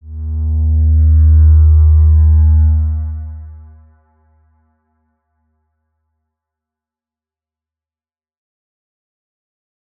X_Windwistle-D#1-ff.wav